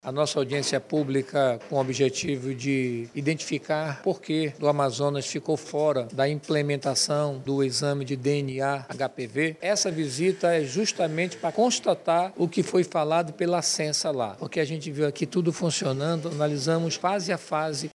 O vereador Gilmar Nascimento, presidente da Comissão de Constituição, Justiça e Redação da CMM, destacou que a fiscalização foi a última etapa de apuração feita pelos parlamentares.